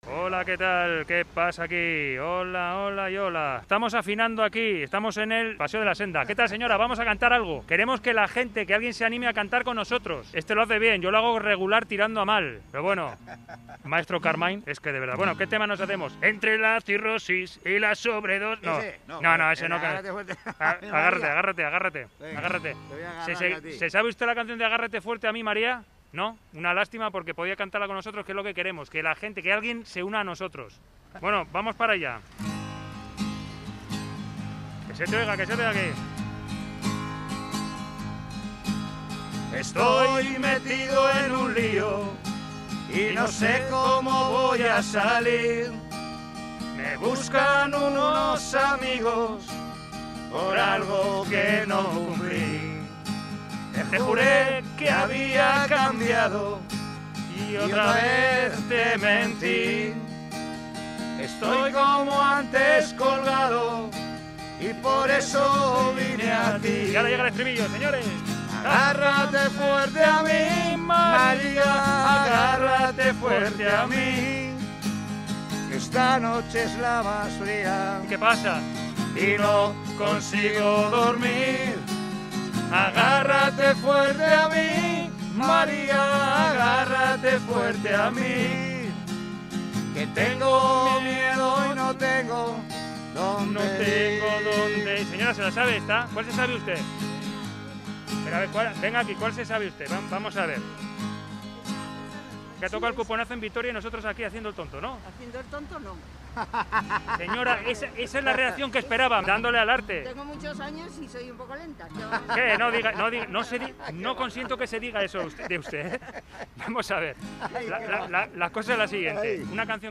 Audio: Nos arrancamos con algunas canciones por Gasteiz. ¿Se animará la gente de Vitoria a cantar con nosotros?